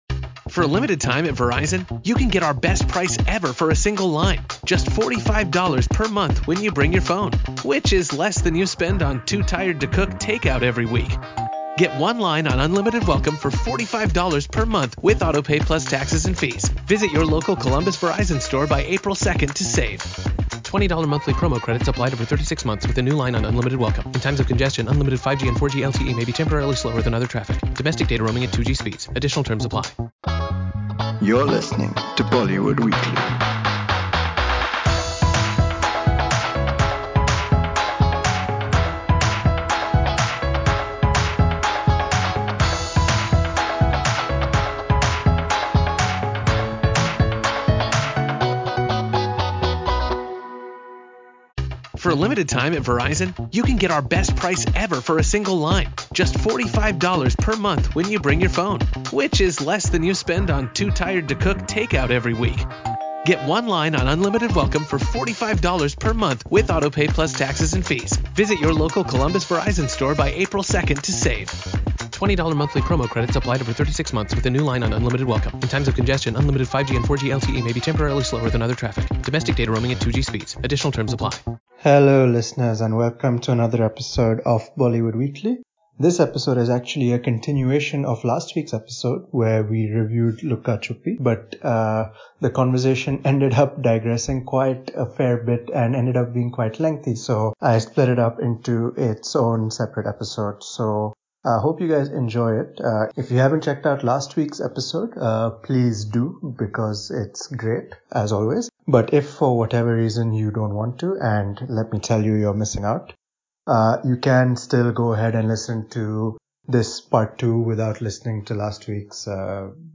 The panel also discusses how content will evolve in the digital age with new OTT players and other disruptions.